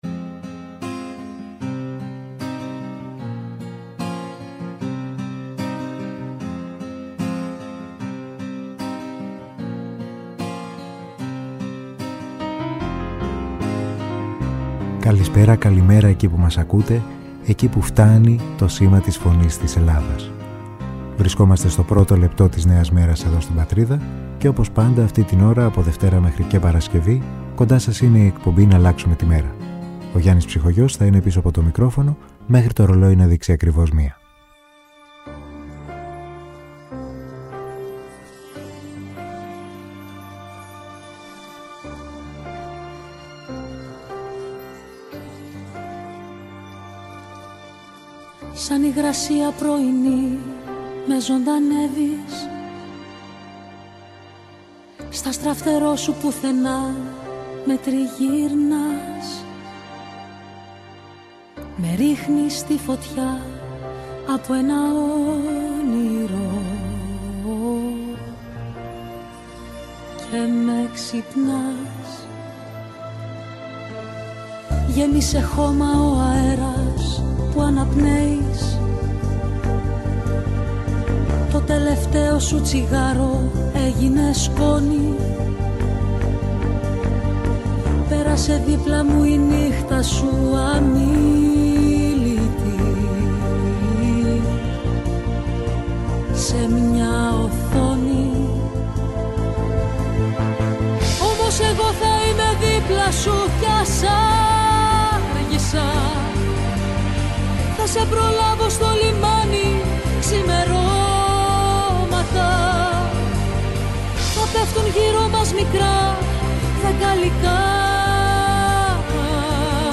Στην αρχή μιας νέας μέρας με μουσικές